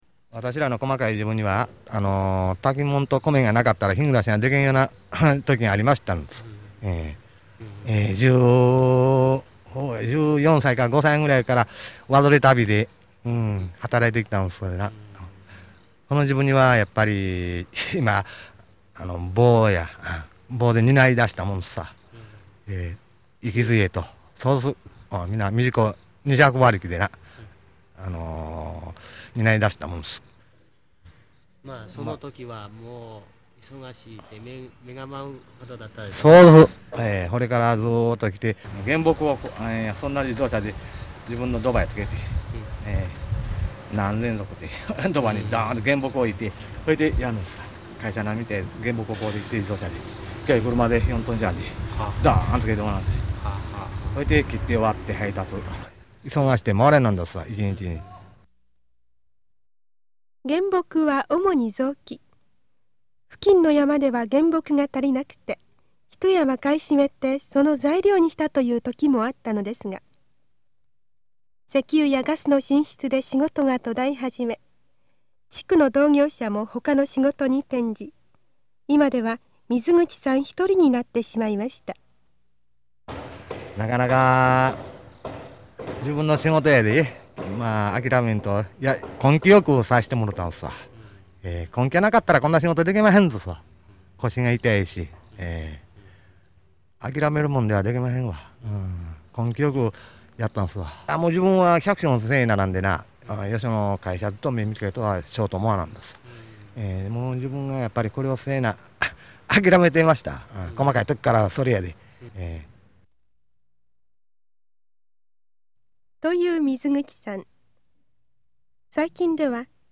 これはその、薪割り仕事の貴重な記録。